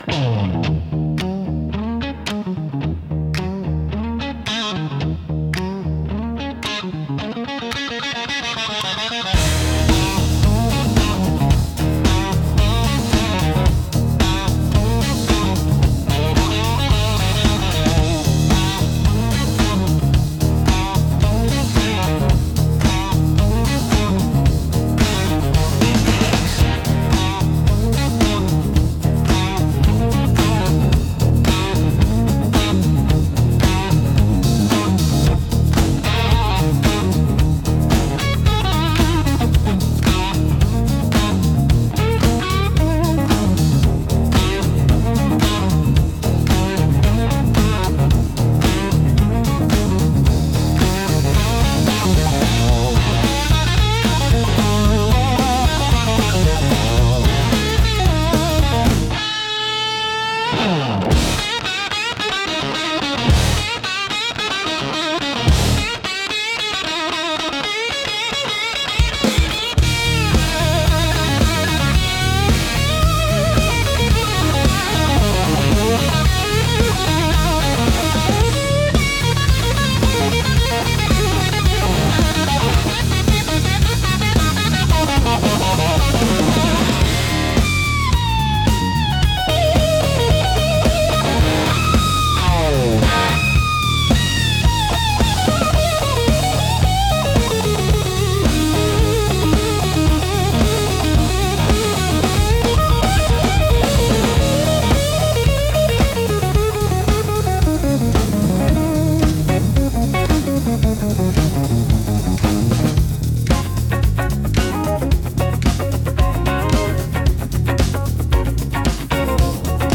Instrumental - Asphalt Hymnal - Grimnir Radio